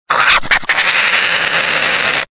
Злобное рычание барсука